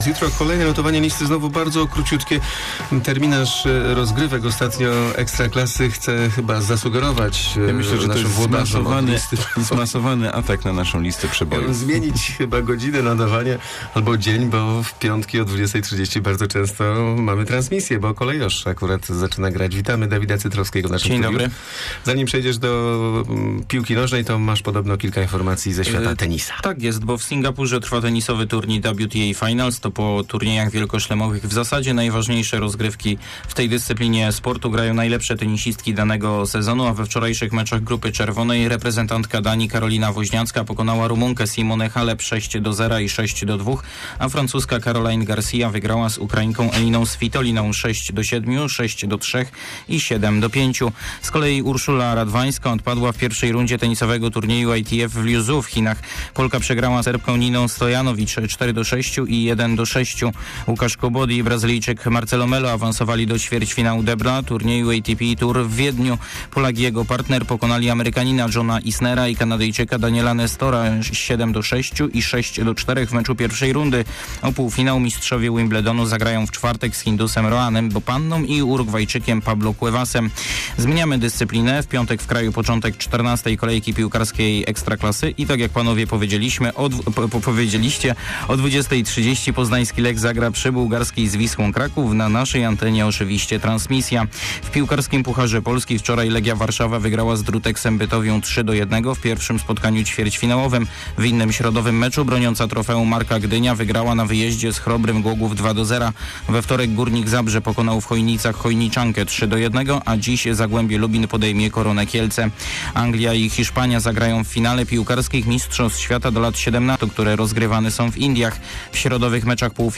26.10 serwis sportowy godz. 7:40